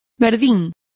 Complete with pronunciation of the translation of scum.